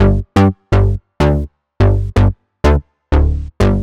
cch_bass_hurled_125_Dm.wav